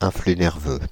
Prononciation
Synonymes potentiel d'action Prononciation France (Île-de-France): IPA: /ɛ̃.fly nɛʁ.vø/ Le mot recherché trouvé avec ces langues de source: français Les traductions n’ont pas été trouvées pour la langue de destination choisie.